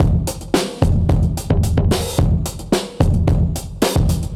Index of /musicradar/dusty-funk-samples/Beats/110bpm/Alt Sound
DF_BeatA[dustier]_110-04.wav